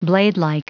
Prononciation du mot bladelike en anglais (fichier audio)
Prononciation du mot : bladelike